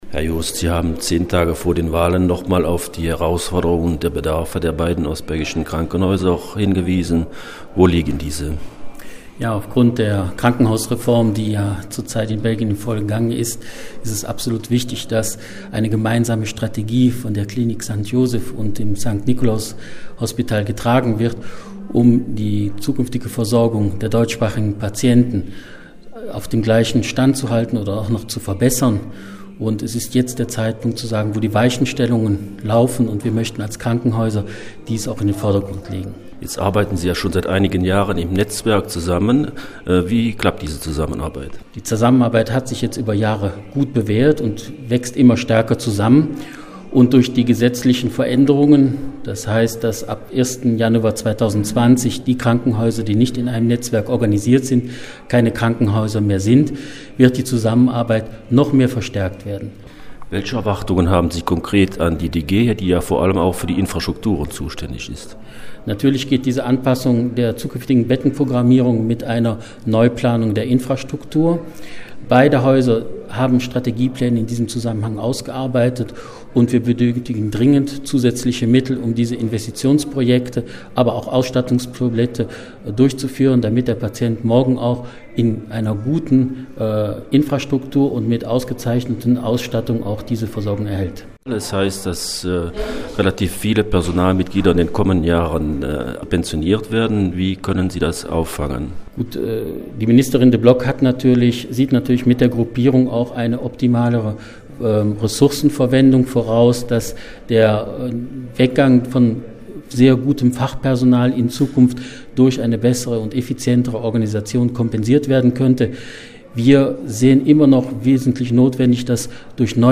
Die Krankenhäuser St.Nikolaus in Eupen und St.Josef in St.Vith, die ja seit einigen Jahren in einem gemeinsam Netzwerk mit dem Lütticher Krankenhauszentrum CHC zusammenarbeiten, wollten die Politiker vor den Wahlen noch mal auf die besonderen Herausforderungen und Bedarfe der Gesundheitsversorgung in der DG hinweisen. Am Rande ihrer Pressekonferenz